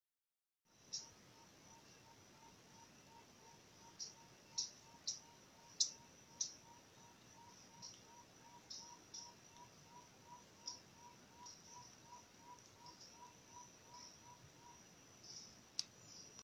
Gallito Overo (Psilorhamphus guttatus)
Nombre en inglés: Spotted Bamboowren
Localidad o área protegida: Parque Provincial Urugua-í
Condición: Silvestre
Certeza: Vocalización Grabada
Gallito-Overo.mp3